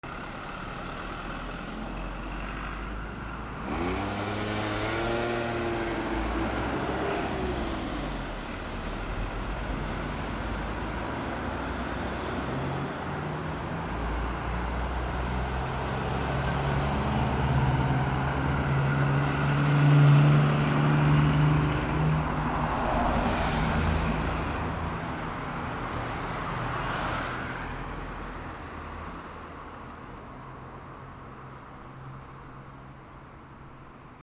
道路
/ M｜他分類 / L30 ｜水音-その他 / 050 環境音 街・道路
ブー